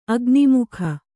♪ agimukha